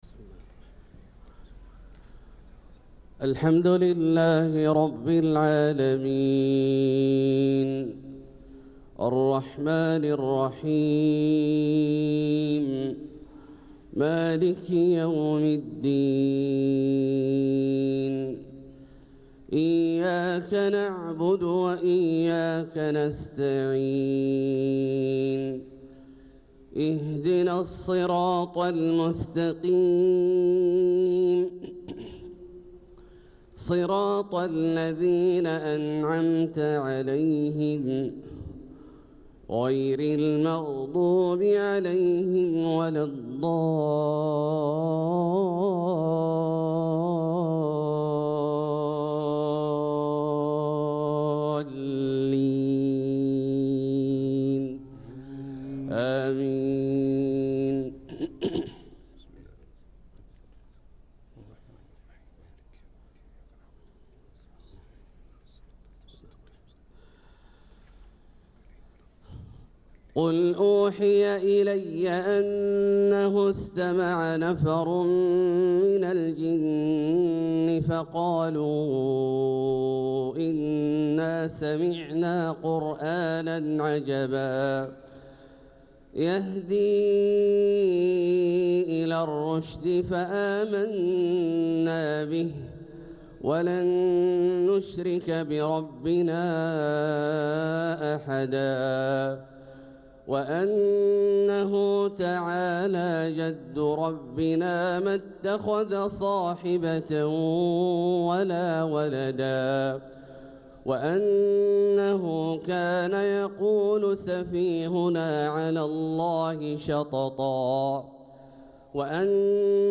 صلاة الفجر للقارئ عبدالله الجهني 26 جمادي الأول 1446 هـ
تِلَاوَات الْحَرَمَيْن .